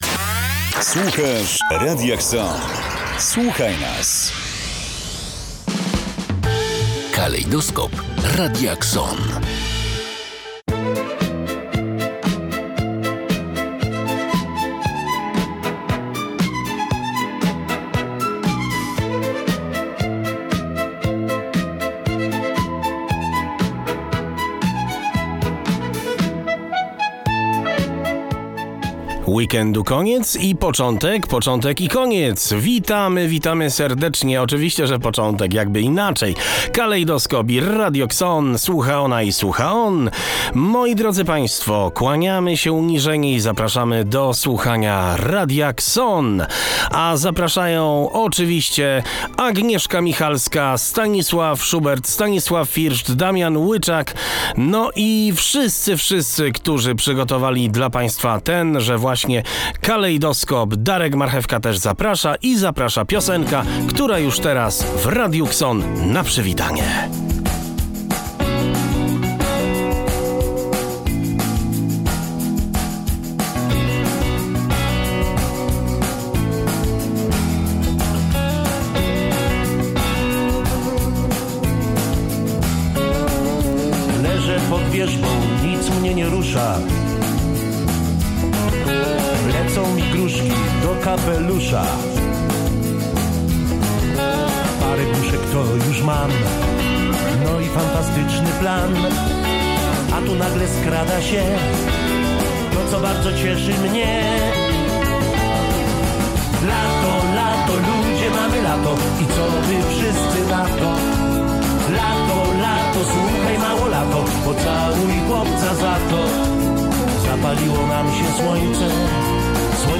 Audycję rozpoczęła piosenka "Lato, lato" i zapowiedź, że będzie to kalejdoskop kulturalno-publicystyczno-społeczny i muzyczny.